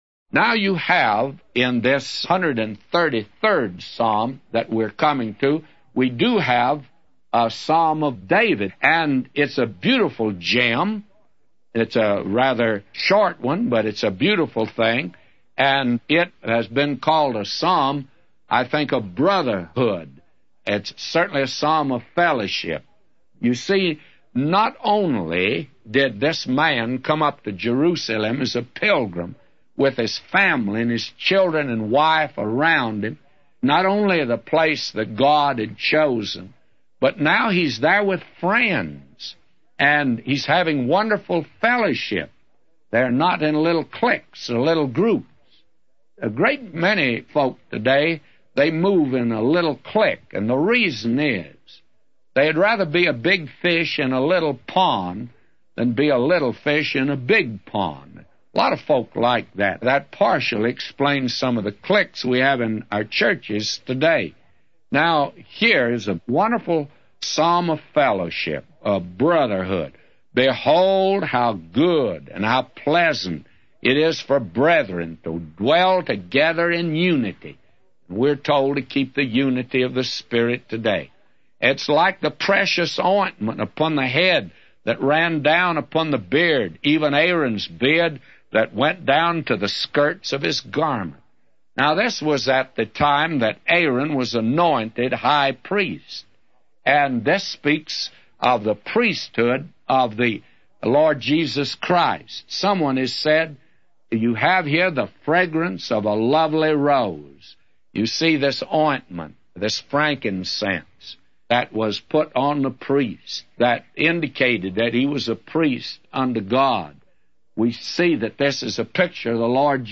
A Commentary By J Vernon MCgee For Psalms 133:1-999